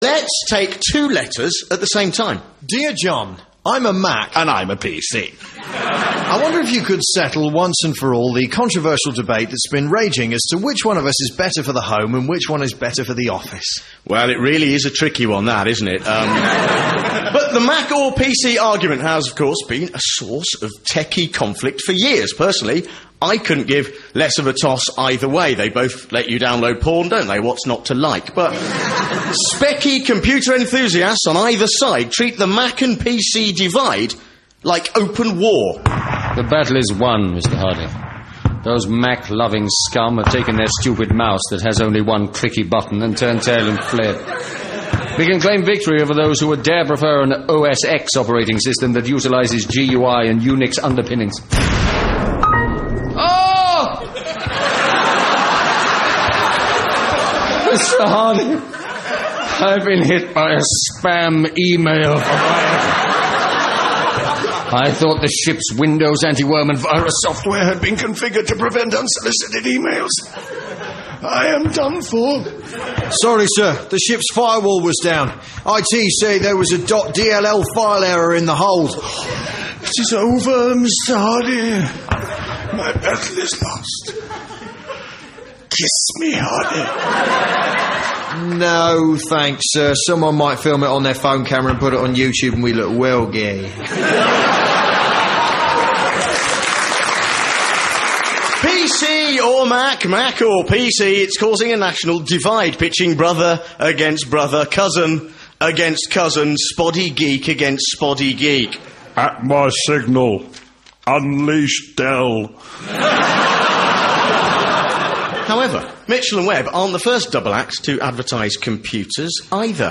I couldn’t be bothered to report Bill Gates getting annoyed on US television over the whole Mac vs. PC thing but I can tell this whole episode has gone mainstream when British comedians start poking fun at the Mac vs. PC ads on BBC Radio 4.